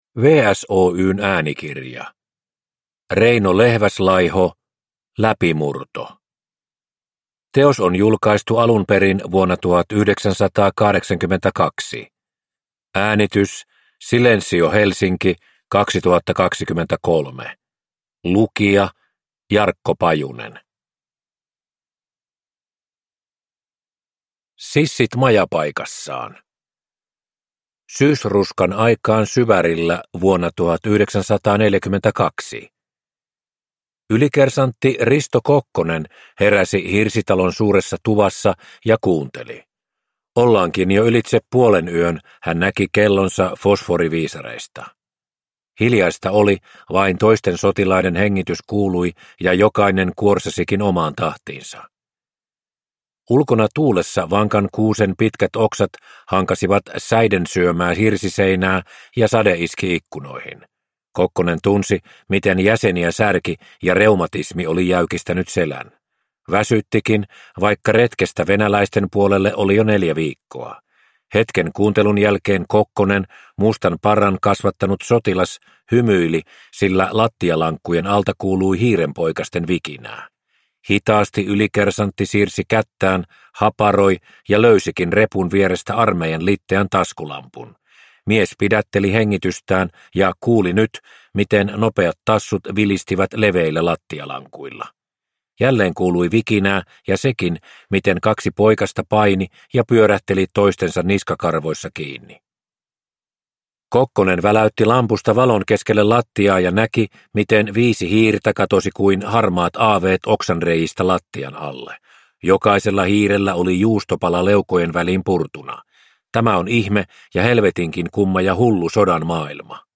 Läpimurto – Ljudbok